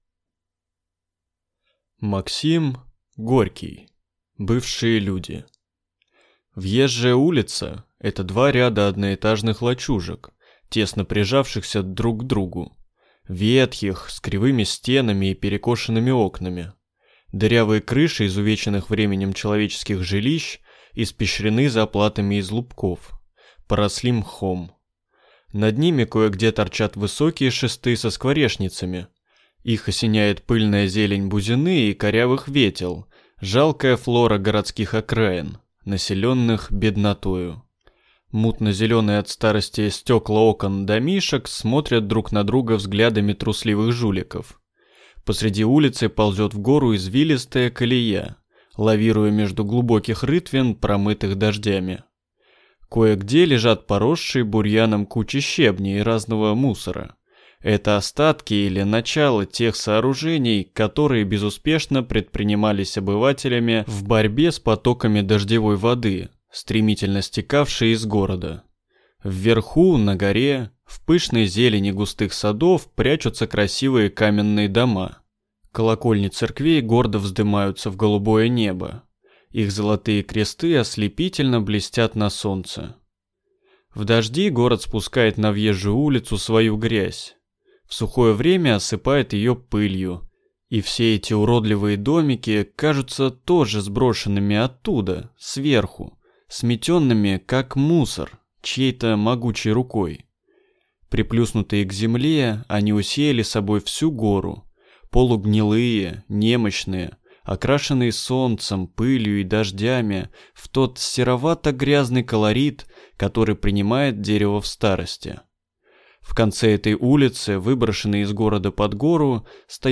Аудиокнига Бывшие люди | Библиотека аудиокниг